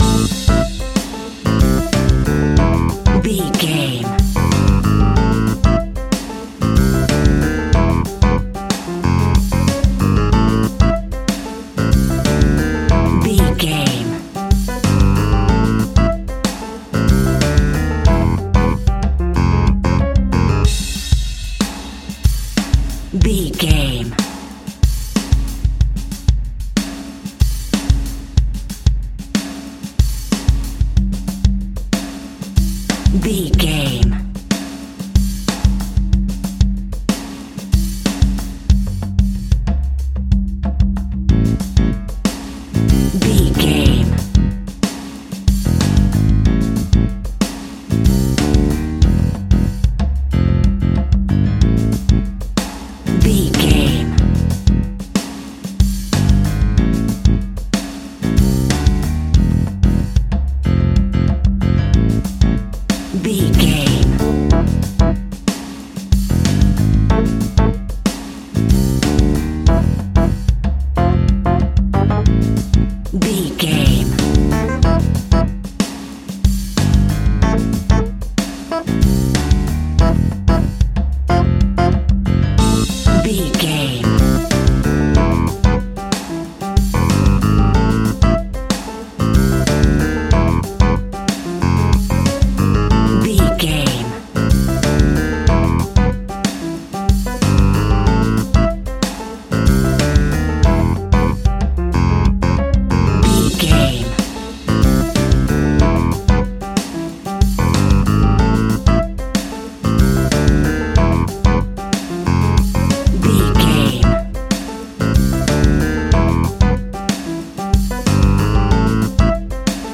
Aeolian/Minor
cuban music
World Music
uptempo
drums
bass guitar
percussion
saxophone
trumpet
fender rhodes